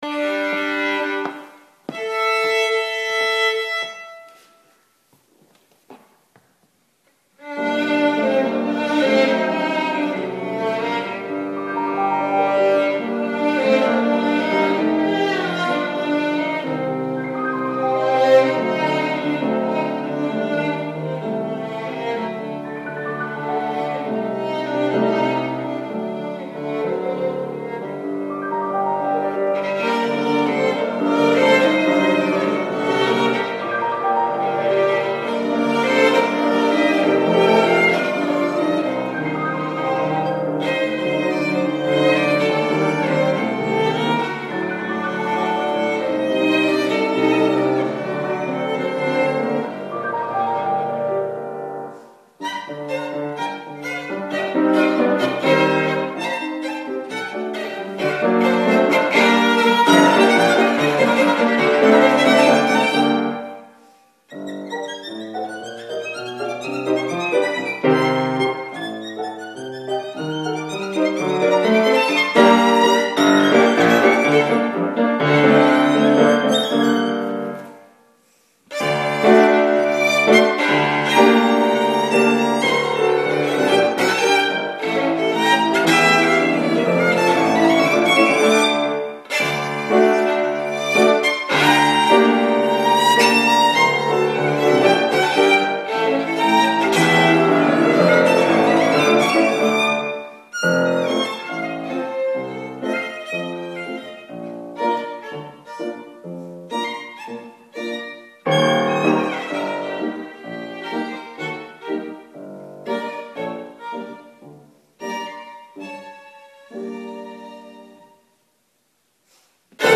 Their broad variety of repertoire ranges from classical music to jazz, pop, ragtime, and Christmas music. They play anything from church music to opera excerpts, and create an especially beautiful ambiance with popular film classics.
Seattle-Violin-Piano-Duo-demo-1.mp3